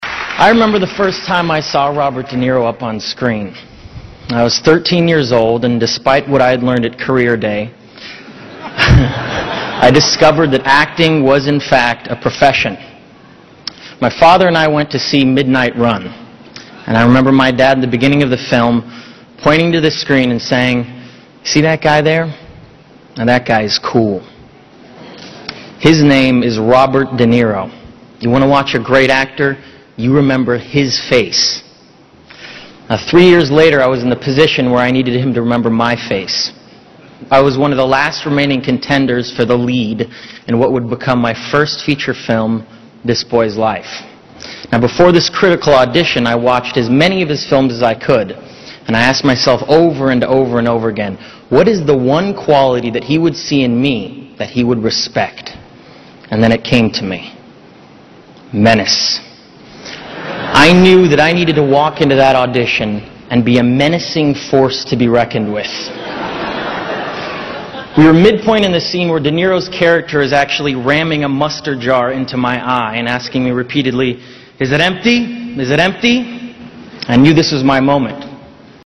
偶像励志英语演讲01:莱昂纳多为德尼罗致辞(1) 听力文件下载—在线英语听力室